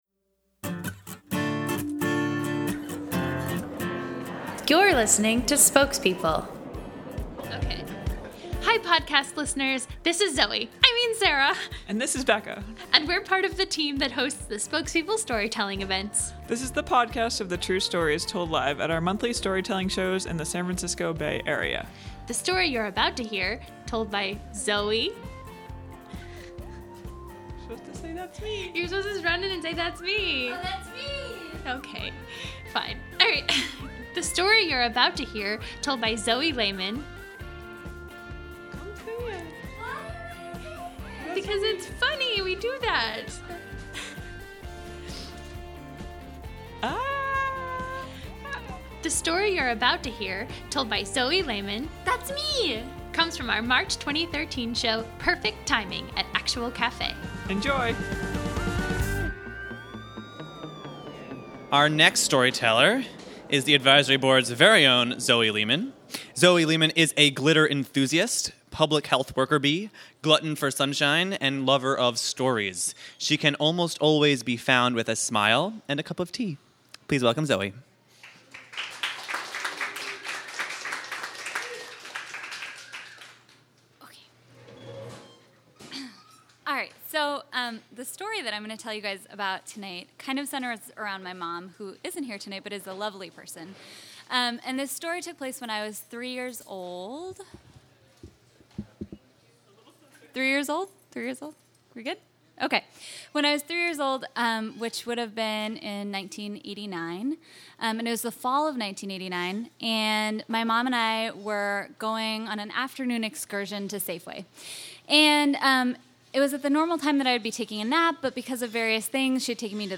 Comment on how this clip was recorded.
This story of a lucky three-year-old and a convenient act of nature comes from our March 2013 show, “Perfect Timing.”